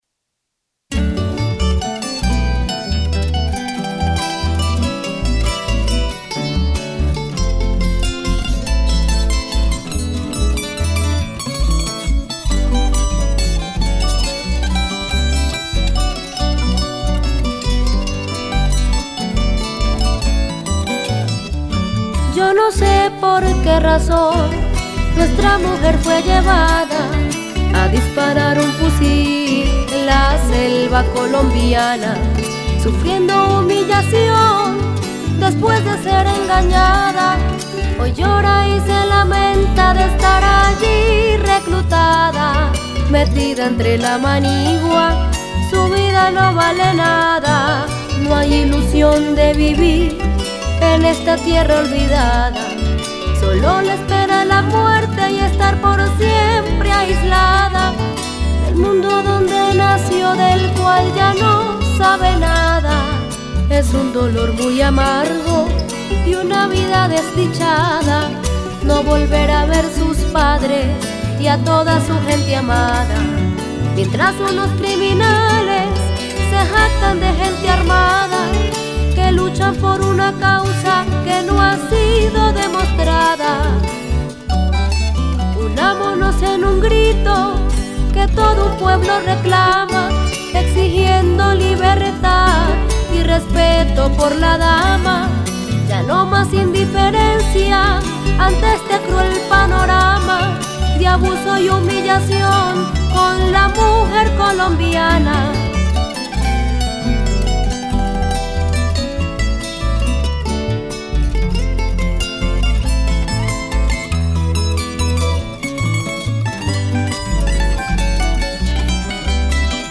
Canción
bandola, tiple, guitarra, bajo y arreglista.